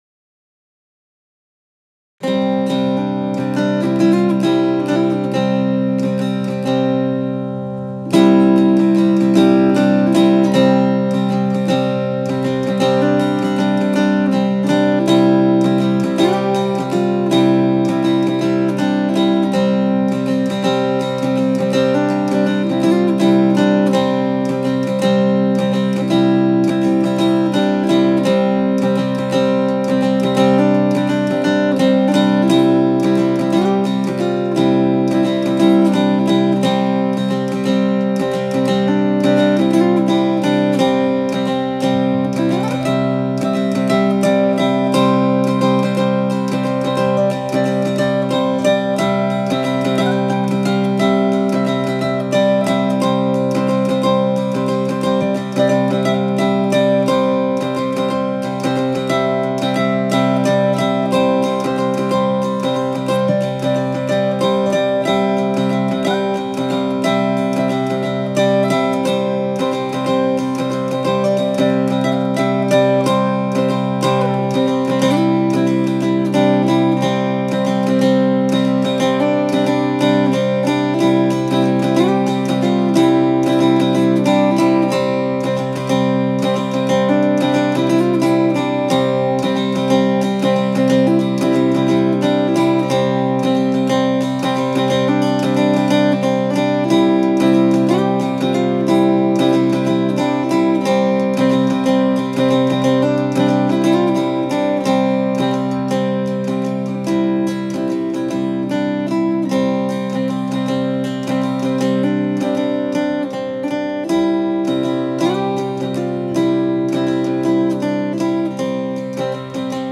This is an instrumental cd (no vocals). Only 1 solo dulcimer was used to record. These hymns are played in a very peaceful and gentle way meant to calm you.